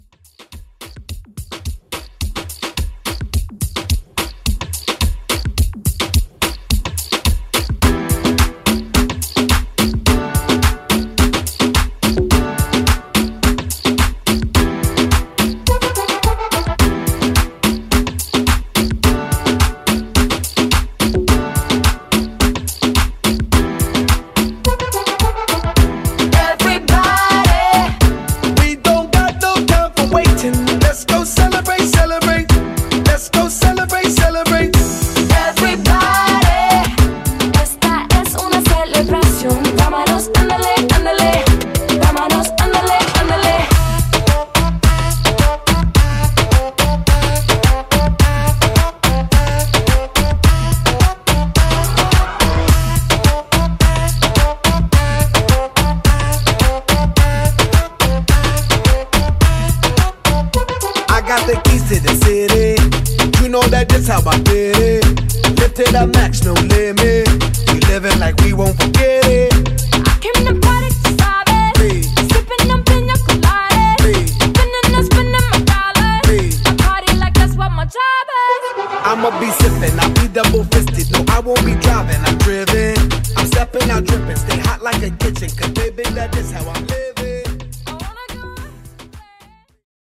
2020 Pop No Hype) Moombah